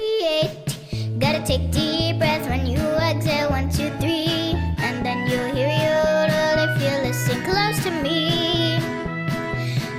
folk.wav